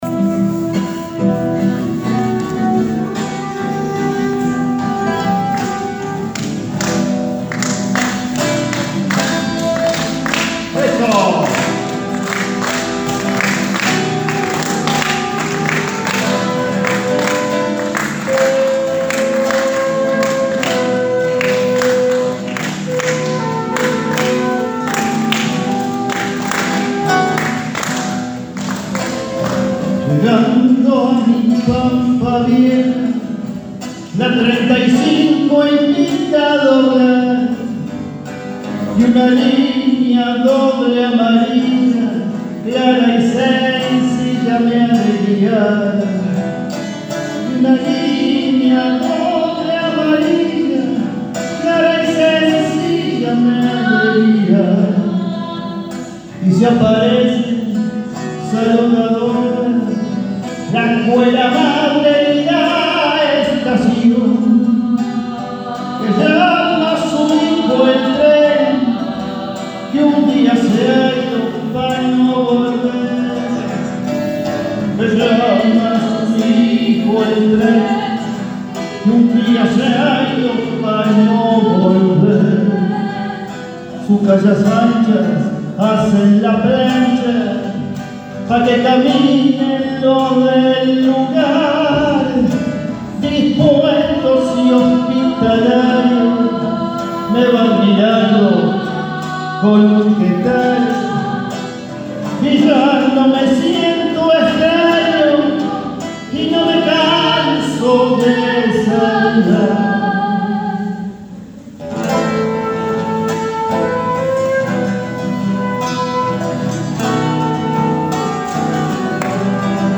En el Día de la Mujer presentaron la zamba «Pa’ Winifreda»
flauta traversa
guitarra
Los artistas unieron sus voces e instrumentos y cantaron la canción. El público acompañó con palmas.
Audio canción «Pa´Winifreda, sonido ambiental.